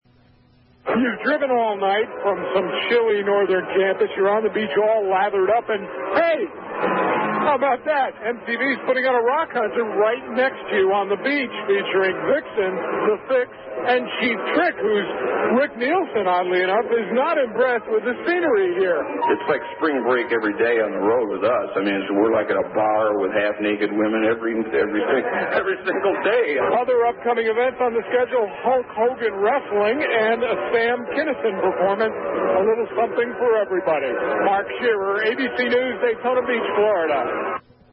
And by 1989, I got sent there by ABC Radio News to report on the scene. This little wraparound sounds like I could have been making it for the Daytona Beach Chamber of Commerce: